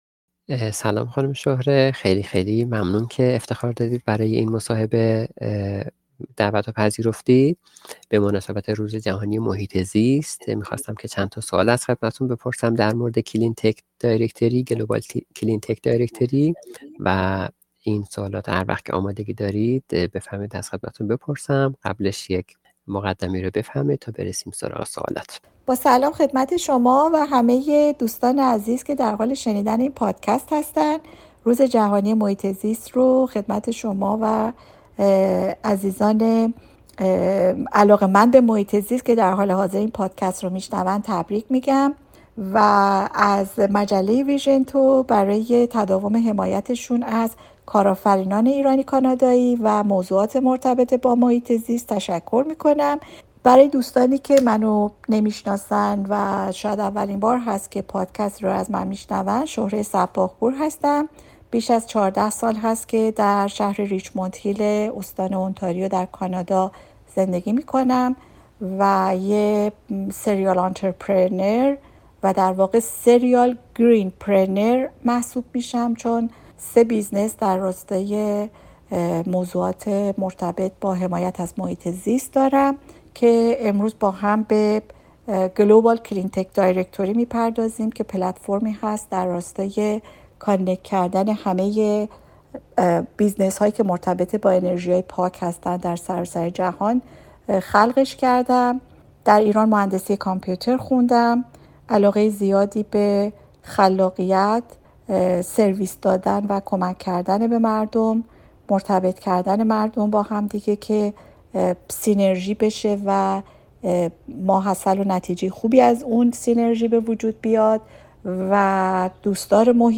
مصاحبه به مناسبت روز جهانی محیط زیست